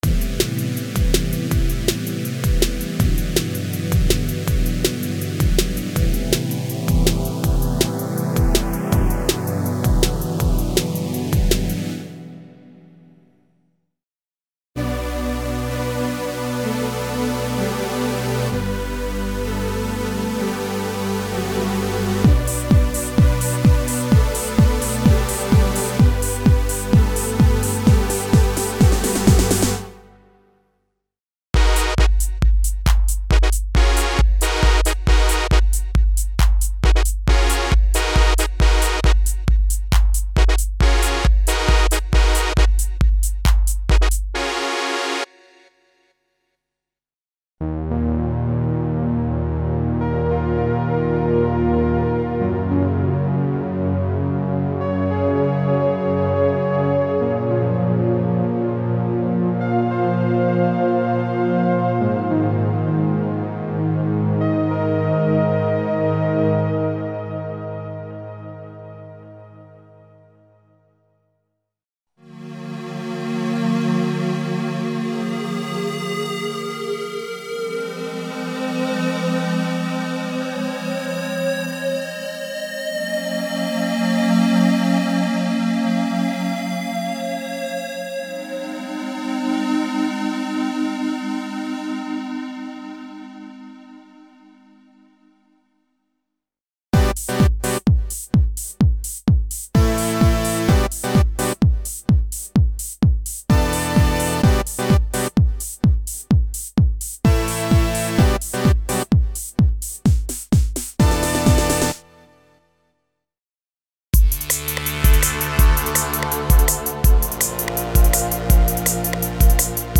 Original collection of analog & digital filtered pads for a wide variety of music styles (Techno, House, Trance, Jungle, Rave, Break Beat, Drum´n´Bass, Euro Dance, Hip-Hop, Trip-Hop, Ambient, EBM, Industrial, etc.).
Info: All original K:Works sound programs use internal Kurzweil K2600 ROM samples exclusively, there are no external samples used.